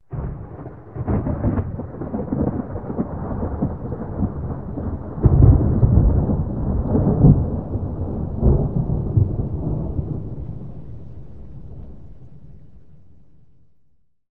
Soundscape Overhaul
thunderfar_9.ogg